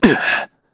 Shakmeh-Vox_Human_Landing_kr.wav